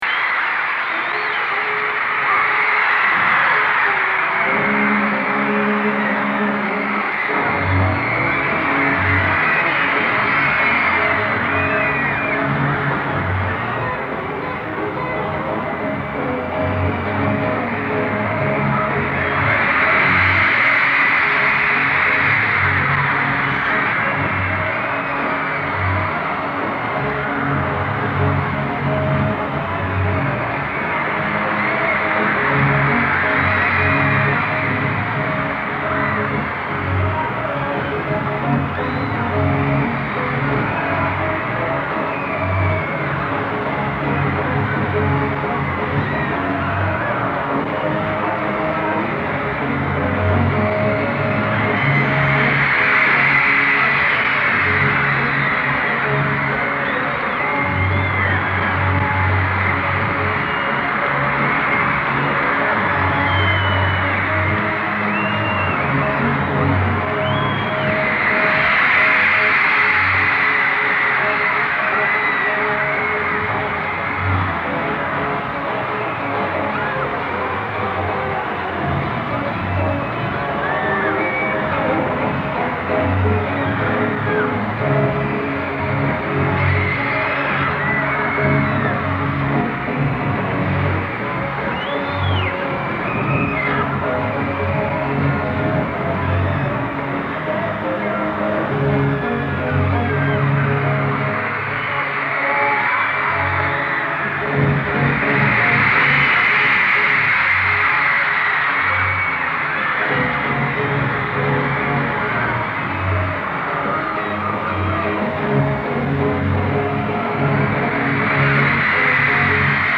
Seattle Center Coliseum, Seattle, WA; August 21, 1964